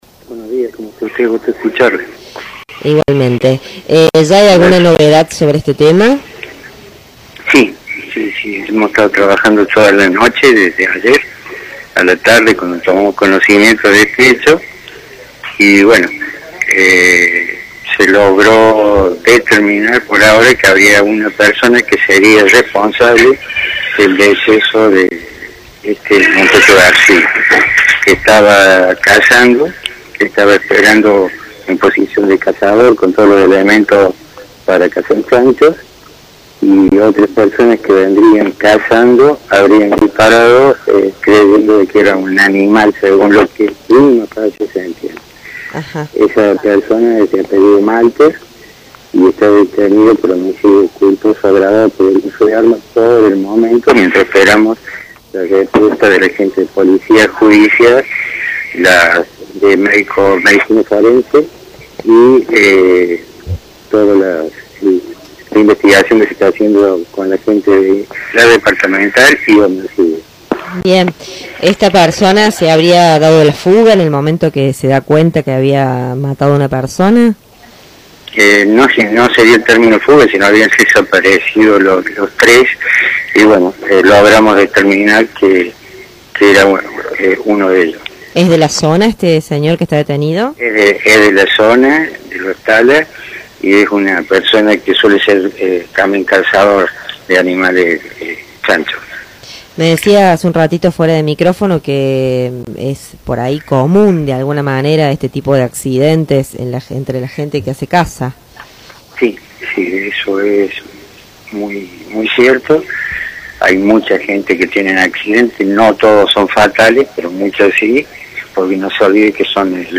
Audio palabras del Fiscal